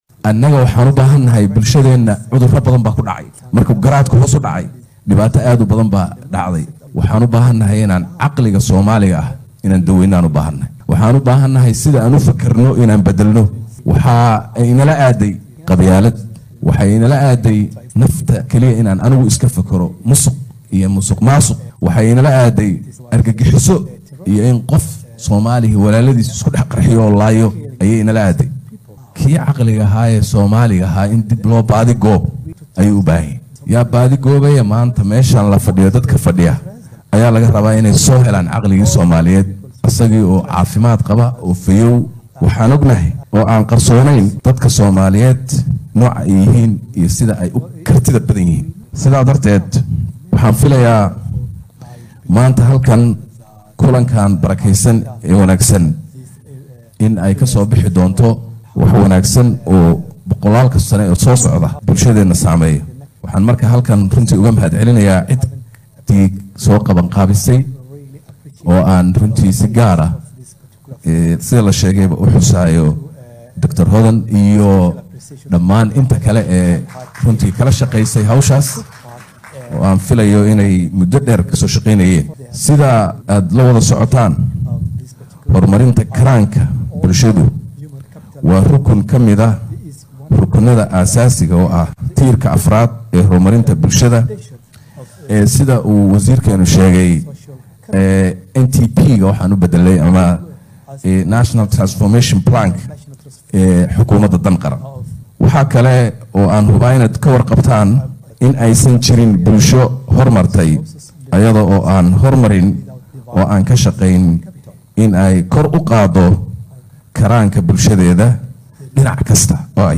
Ra’iisul wasaaraha xukuumadda Soomaaliya Xamse Cabdi Barre ayaa sheegay in loo baahan yahay in la daweeyo caqliga soomaaliga iyo sida ay u fikirayaan. Waxaa uu xusay in bulsho karaankeedu hooseeyo aysan la loolami karin caalamka intiisa kale. Hadalkan ayuu maanta jeediyay, xillii uu furay shir ku saabsan kobcinta karaanka aadanaha oo lagu qabtay magaalada Muqdisho ee caasimadda dalka Soomaaliya.